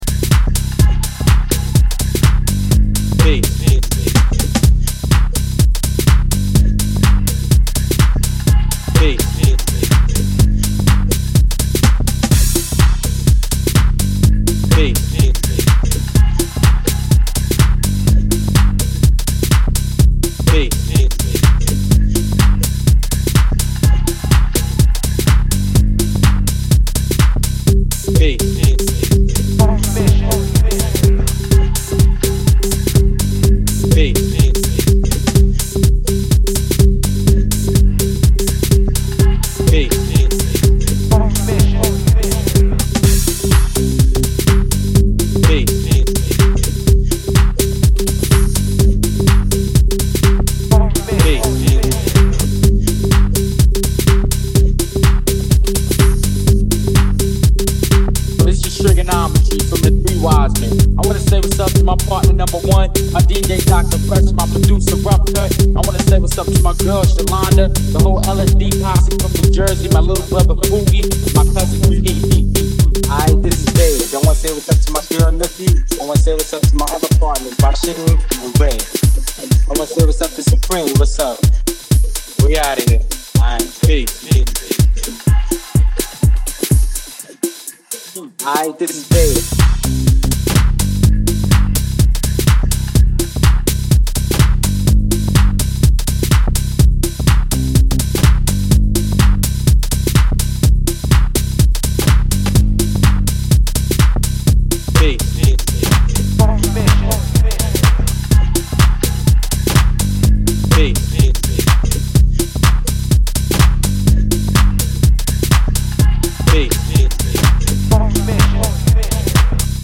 house and garage
a bubbly mix of top and hits over driving bass notes